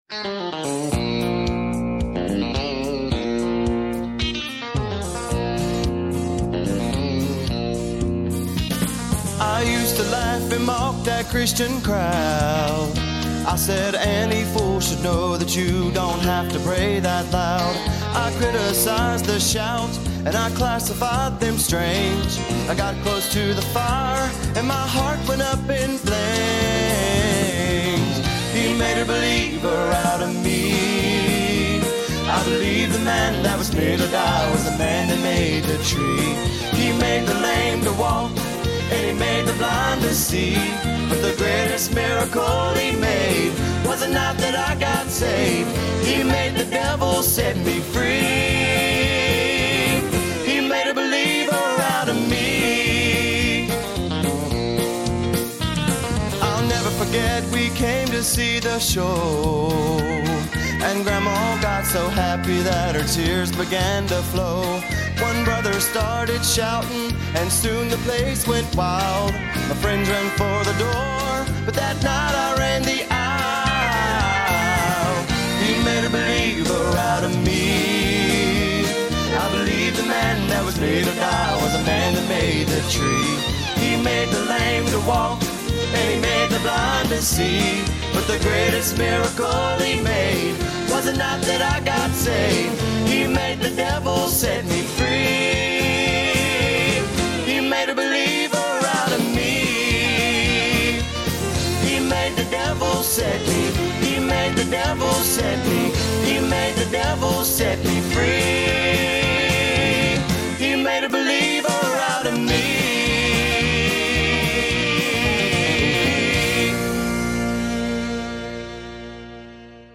The most dirt country gospel song known to man!